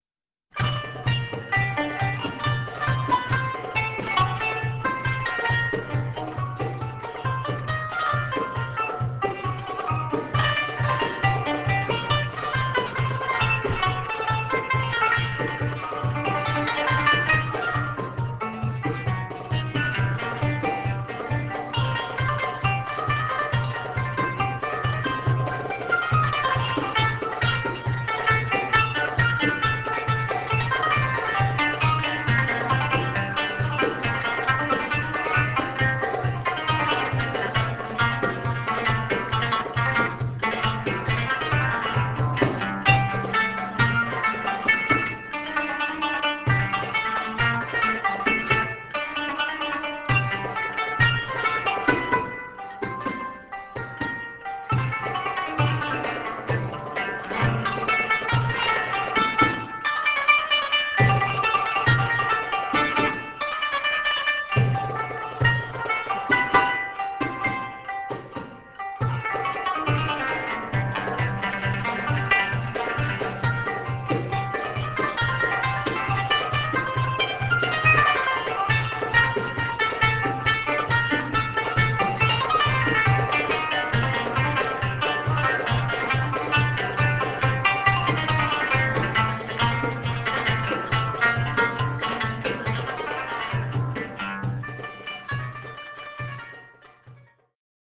Arabic w/improvised taqasim